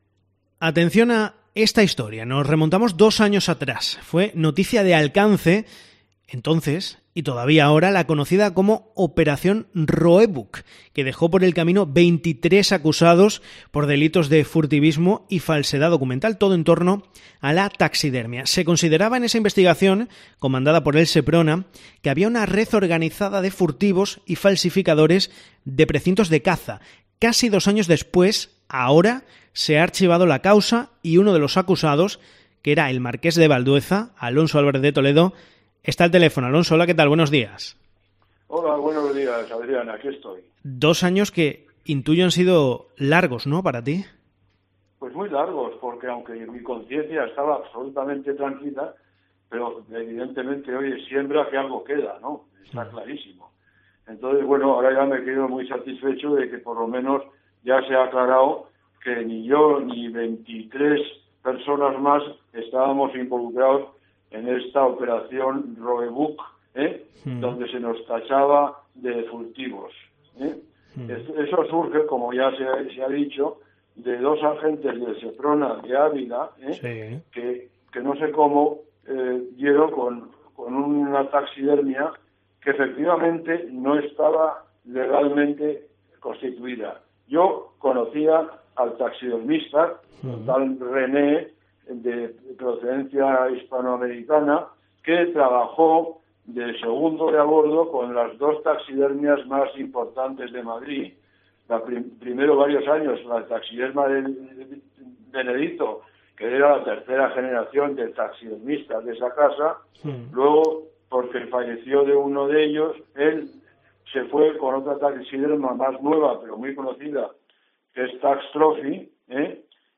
Y lo ha hecho en COPE Extremadura.